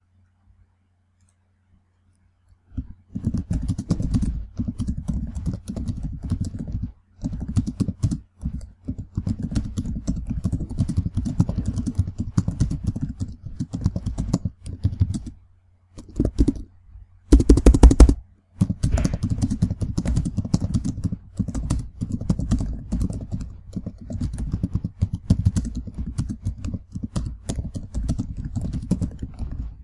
电脑键盘的击键
描述：在电脑键盘上打字。
Tag: 键盘 办公 电脑 打字